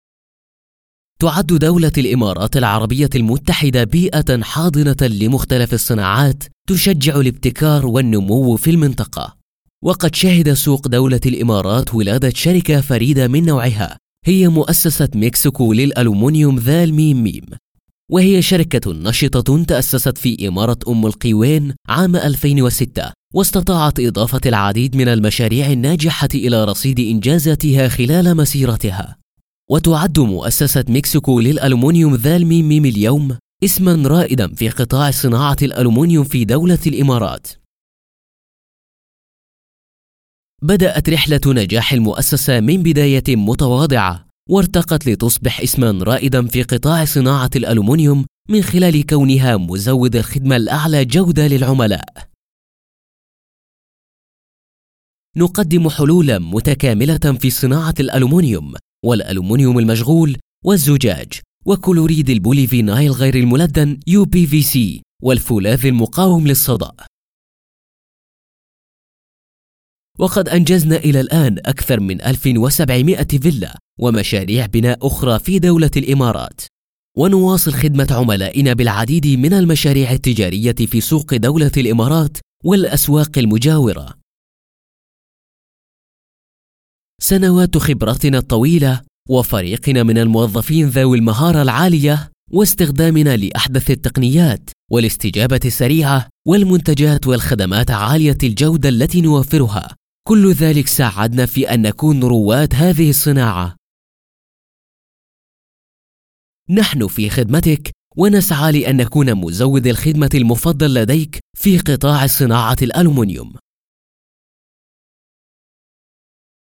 Arabic Female Voice Artist